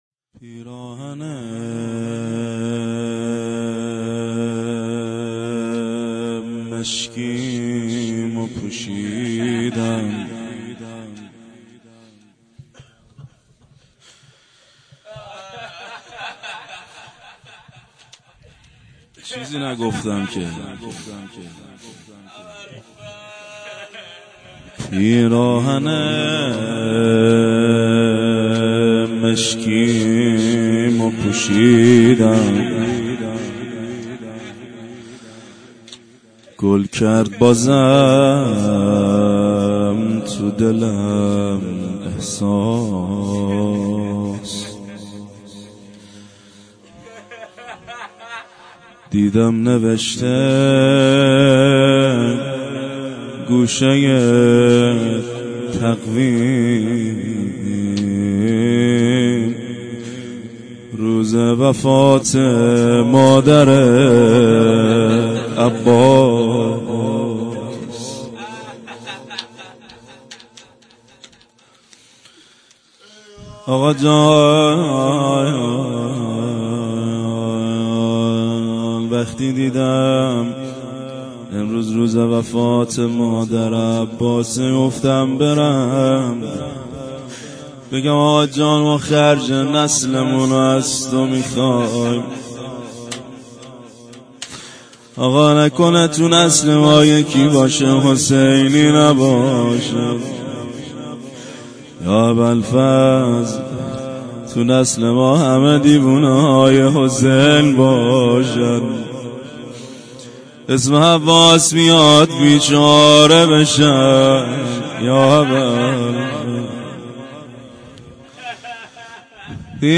روضه حضرت ام البنین(س)
هفتگی - وفات حضرت ام البنین س